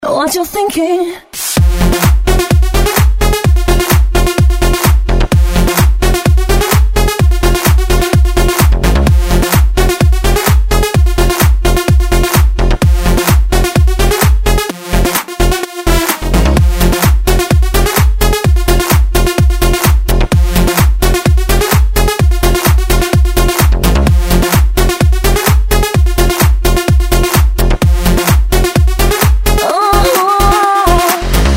Vocal mix
из Клубные